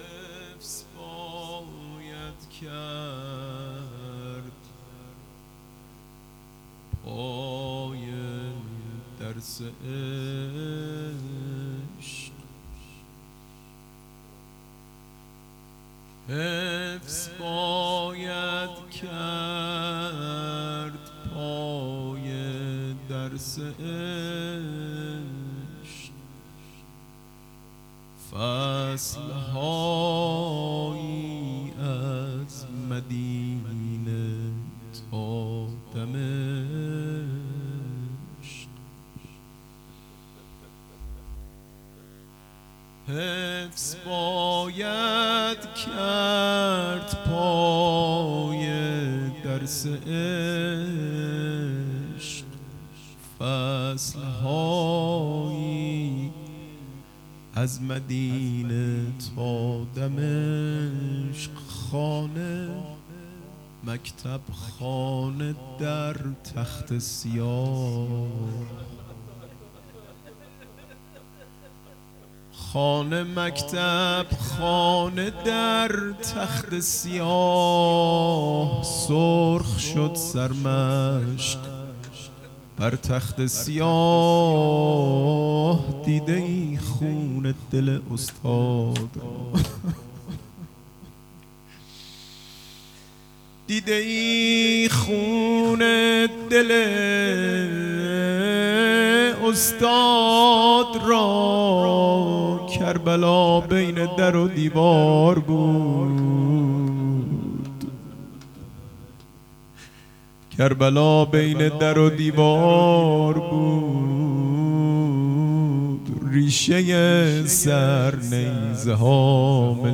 شب شهادت حضرت زهرا ۱۴۰۲